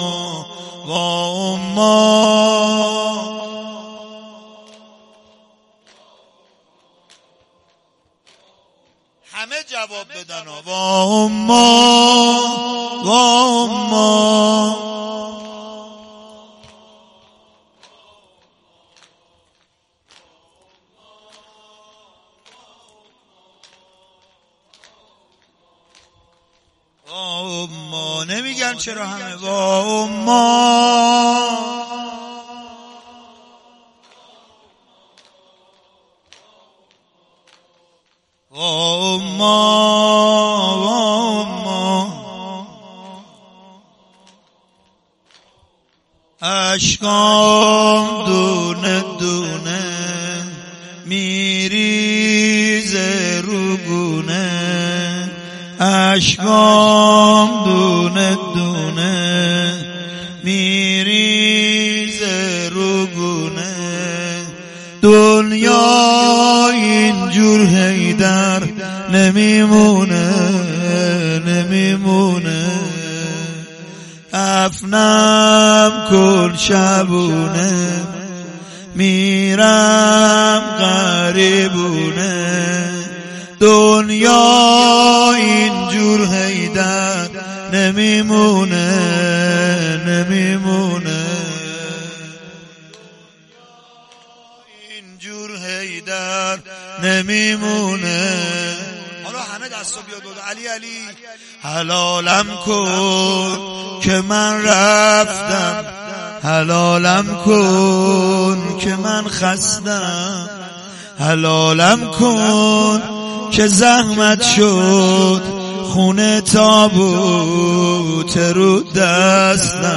سینه زنی.mp3
سینه-زنی.mp3